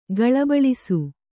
♪ gaḷabaḷisu